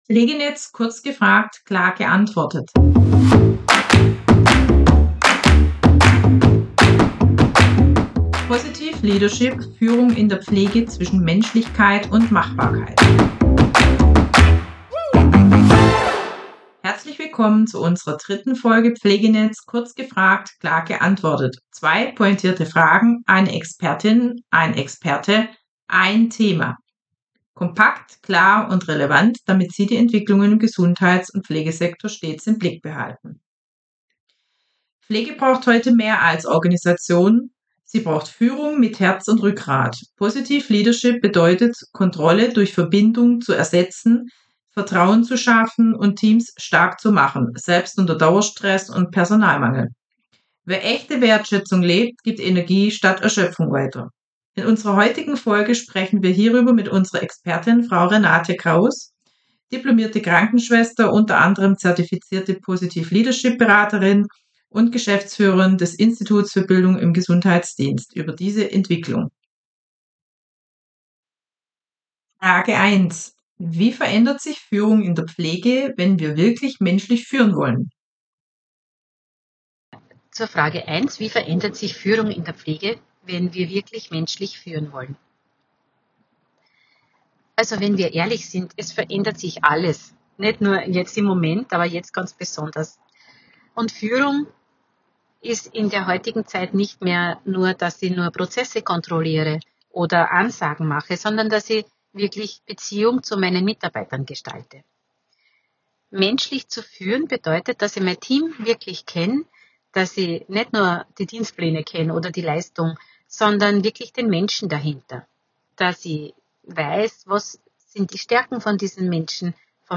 Zwei prägnante Fragen, eine Expertin oder ein Experte, ein Thema – kompakt, klar und relevant damit Sie die Entwicklungen im Gesundheits- und Pflegesektor stets im Blick behalten.